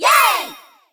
yay.wav